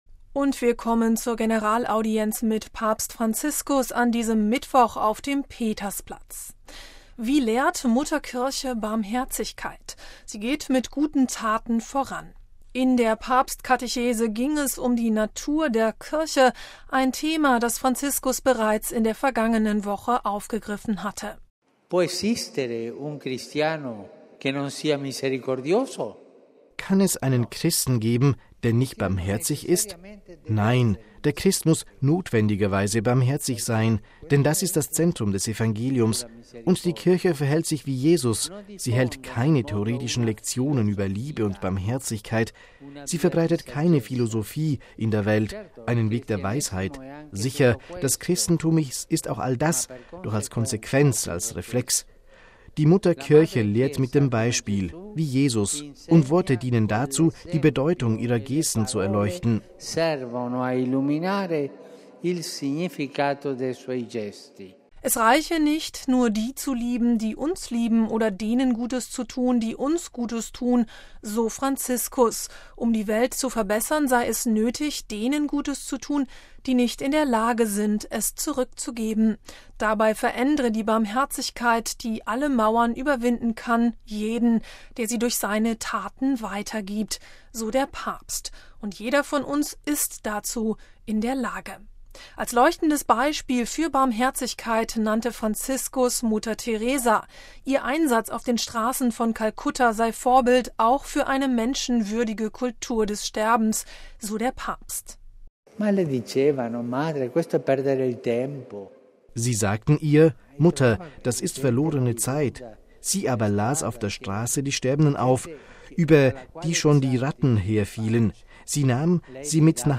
Das war Franziskus‘ Botschaft an diesem Mittwoch bei der Generalaudienz auf dem Petersplatz. In seiner Katechese ging es um die Natur der Kirche, ein Thema, das der Papst bereits in der vergangenen Woche vertieft hatte.